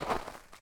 footstep_snow6.ogg